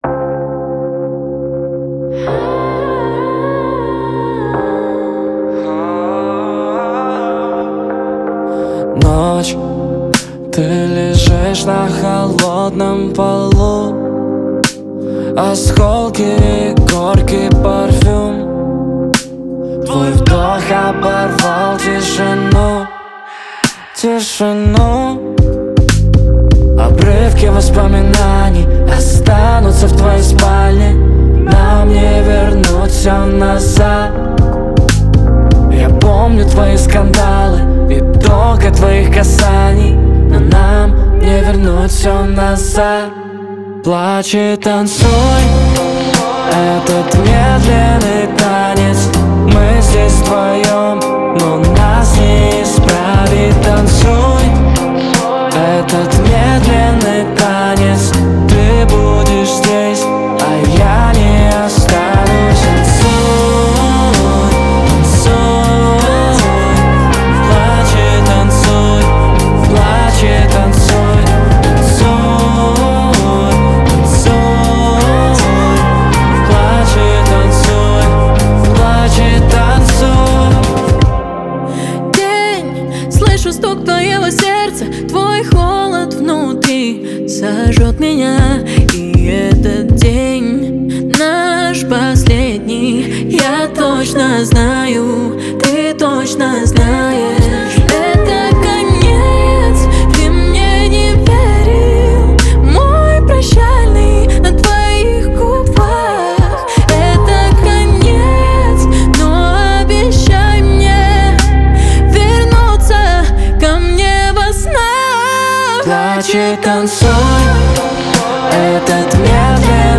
Исполнитель джазовой, соул и традиционной поп  музыки.